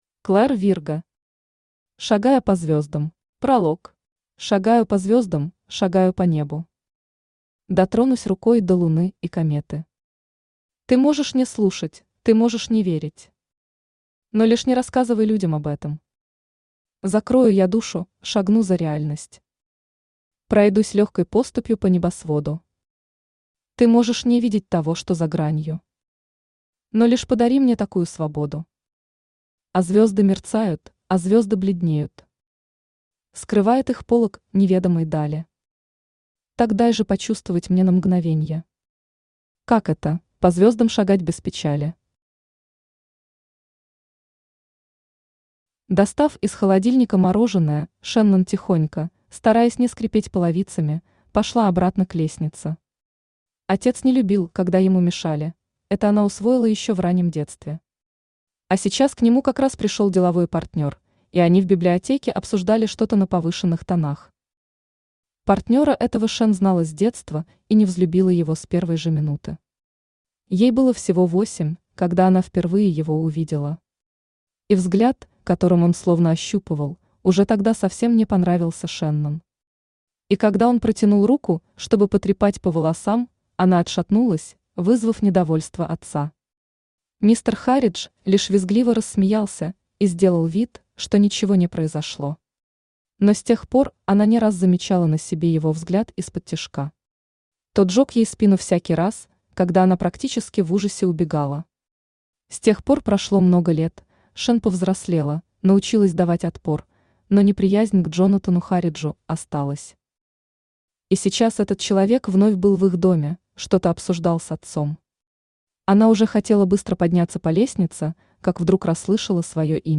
Аудиокнига Шагая по звездам | Библиотека аудиокниг
Aудиокнига Шагая по звездам Автор Клэр Вирго Читает аудиокнигу Авточтец ЛитРес.